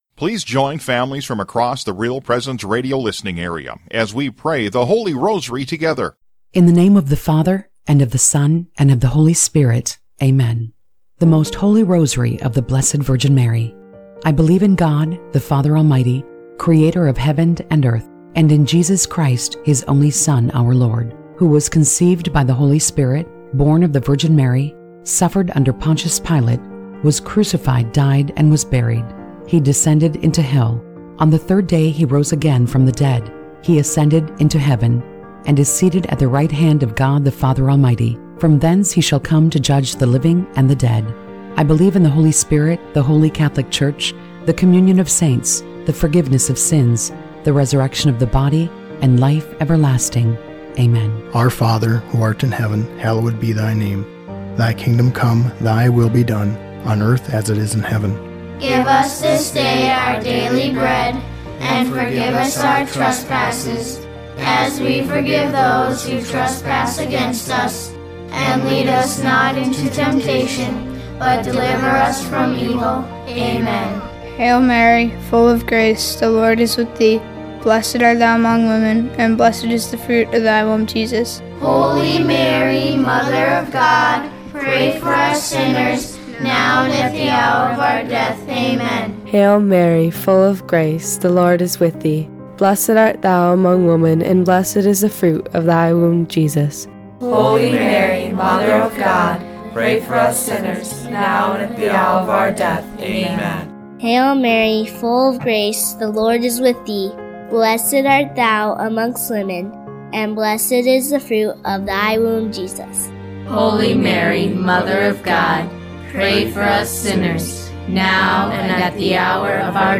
We invite you to pray our special presentation of the Luminous Mysteries of the RPR Family Rosary. We visited families throughout our listening area and recorded this beautiful prayer with moms, dads, and their children.